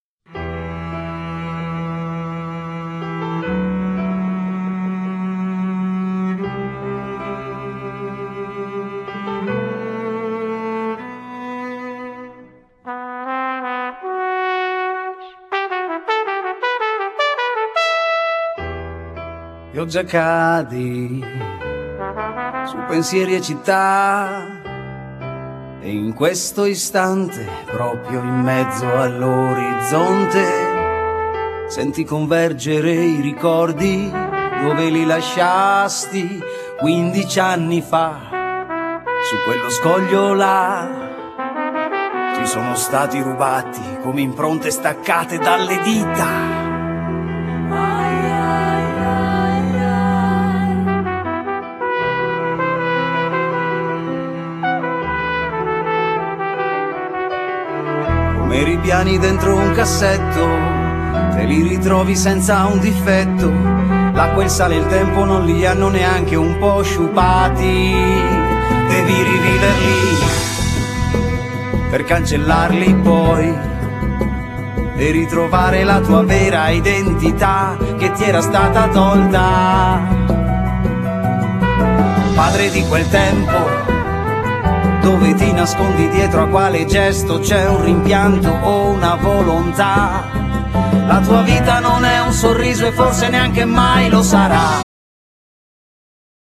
Genere : Pop